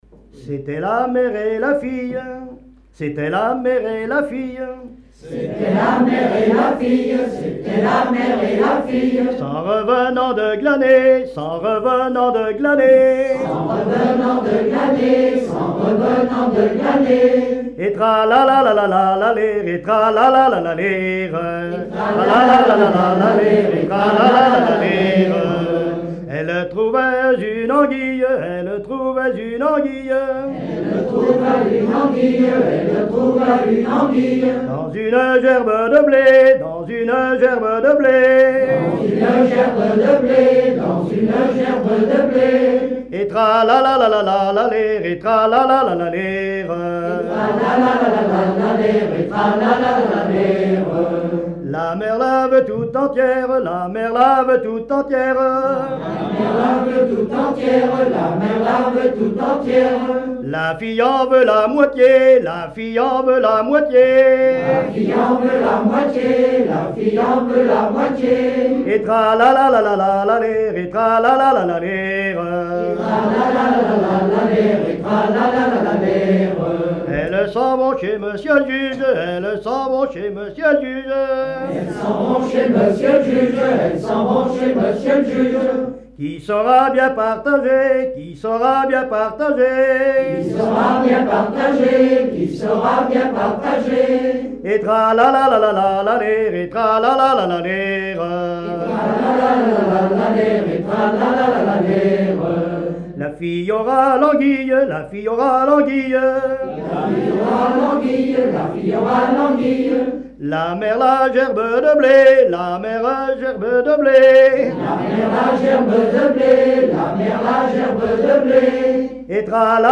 Genre laisse
Veillée
Pièce musicale inédite